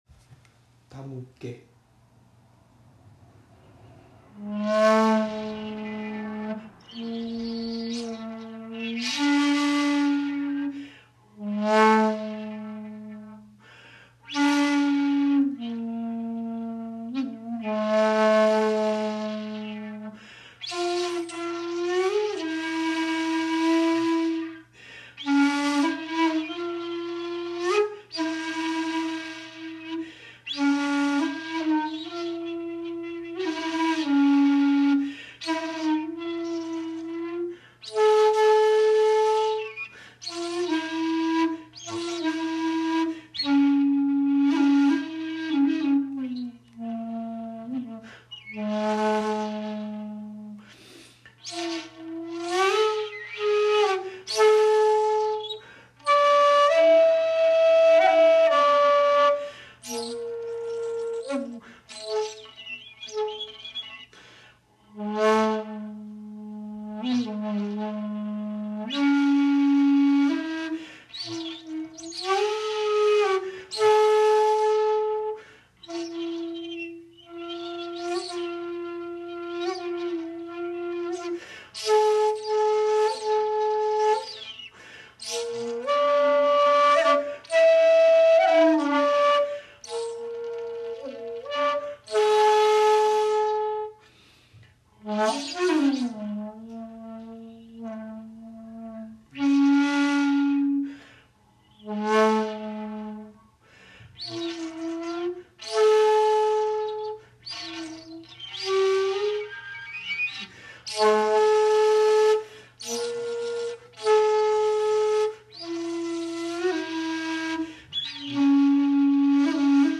まず大窪寺に納経し、尺八を吹奏し奉納しました。
（尺八音源：大窪寺にて「手向」 ）